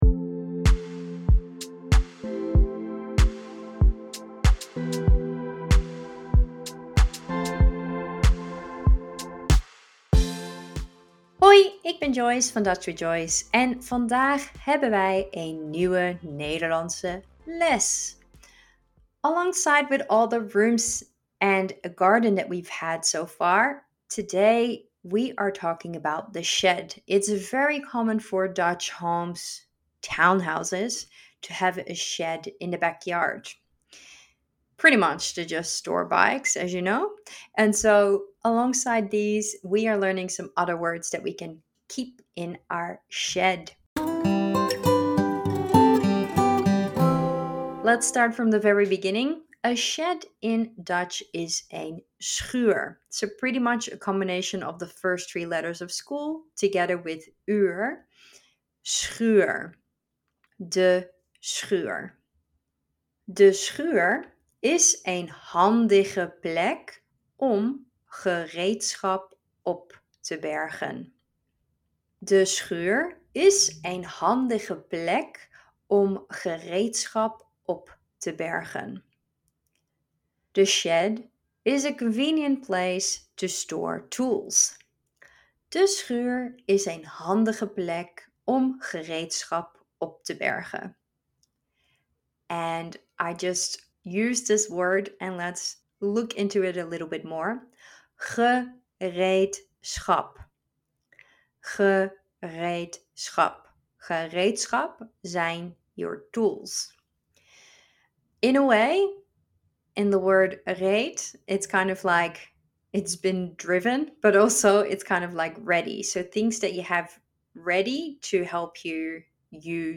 This mini-lesson is a perfect follow-up on last week's episode about the garden